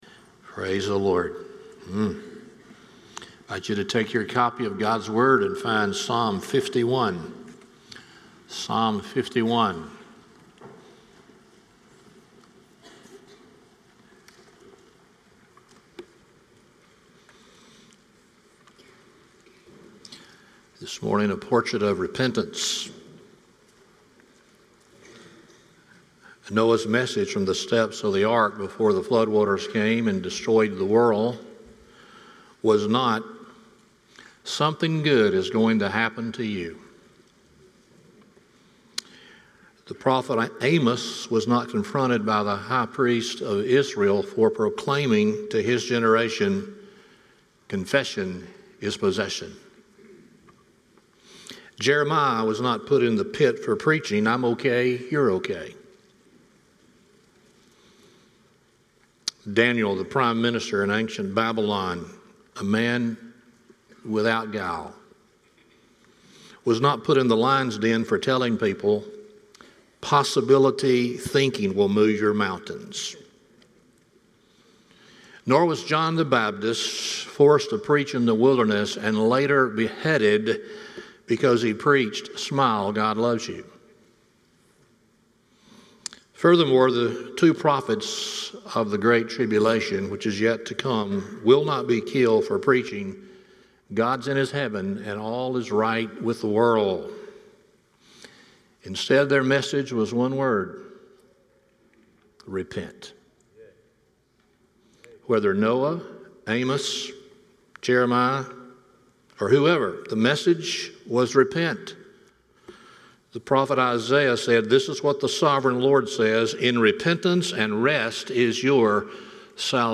Series: Stand Alone Sermons
Psalm 51:1-17 Service Type: Sunday Morning WHAT DOES IT MEAN TO "REPENT"? 1